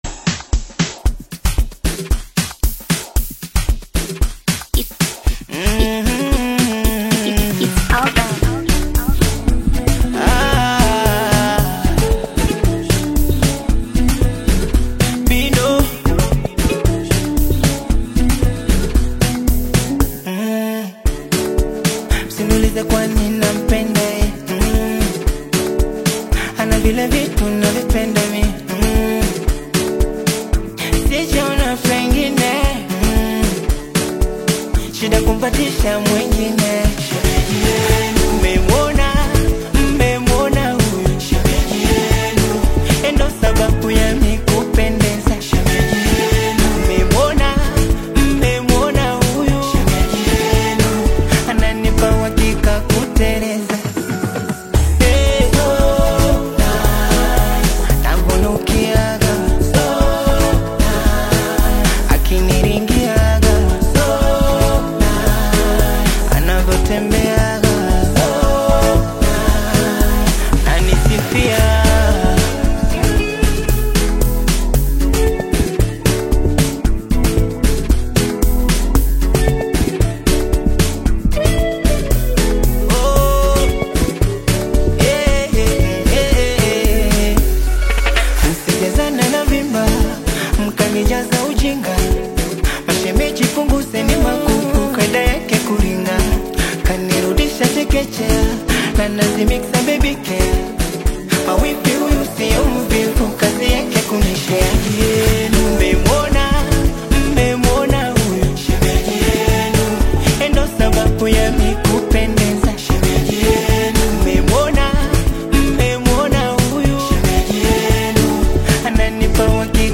emotive Afro-Pop/Bongo Flava track